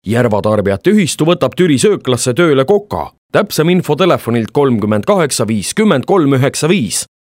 Professioneller estnischer Sprecher für TV/Rundfunk/Industrie.
Sprechprobe: Industrie (Muttersprache):
Professionell estnian voice over artist